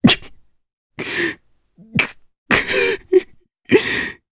Crow-Vox_Sad_jp.wav